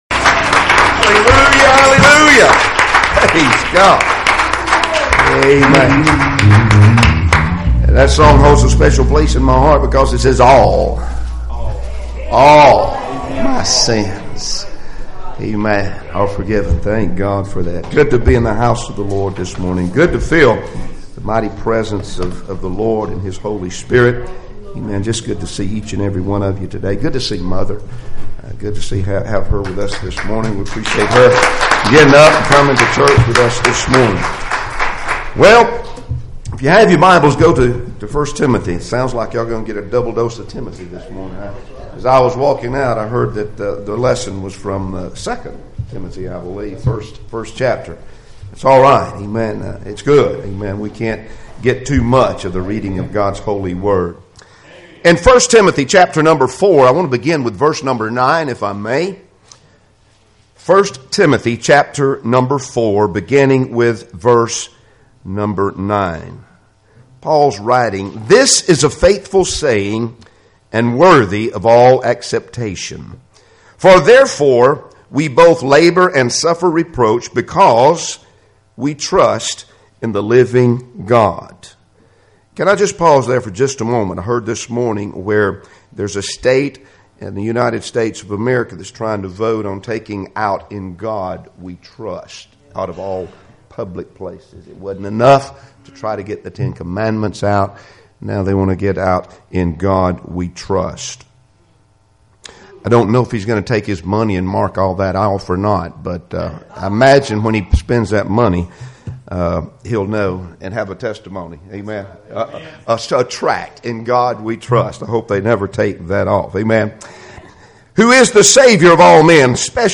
1 Timothy 4:9-14 Service Type: Sunday Morning Services Topics